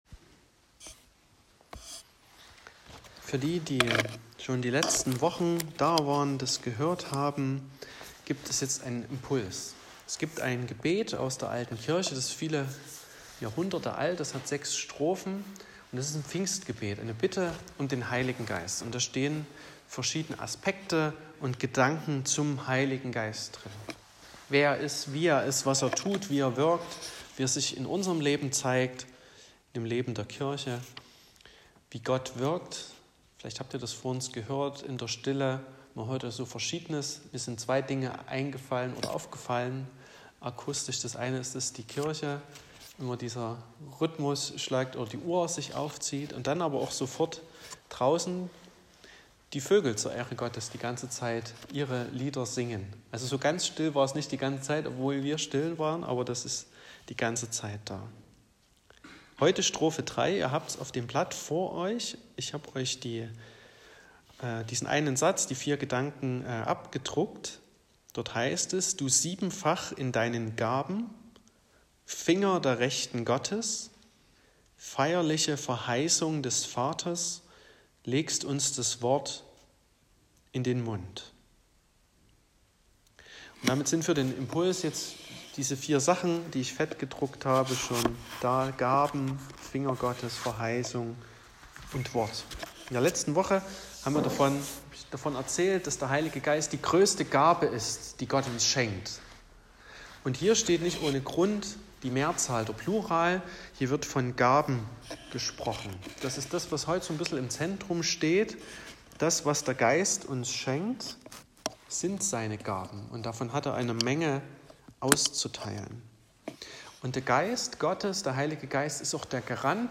Predigt und Aufzeichnungen